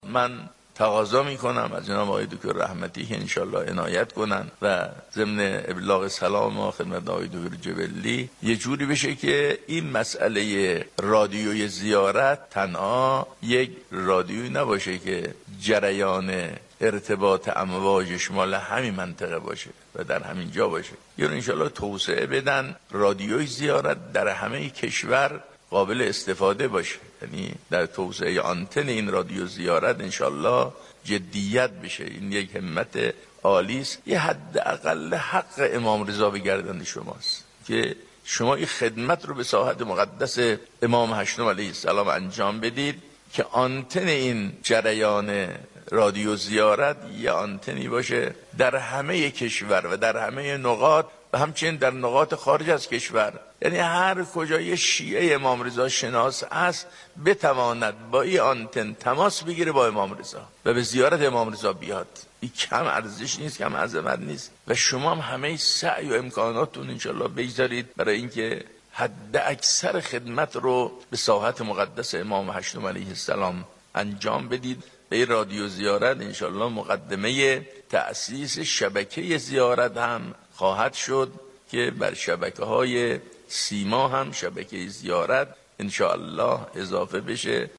نماینده ولی وفقیه در خراسان رضوی و امام جمعه مشهد در اختتامیه نخستین جشنوراه تولیدات رادیویی زیارت گفت:آنتن رادیو زیارت باید حتی در نقاط خارج از كشور برای شیعیان خارج از ایران در دسترس باشد تا شیعیان بتوانند با امام معصوم ارتباط بگیرند.